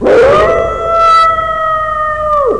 growl_6.mp3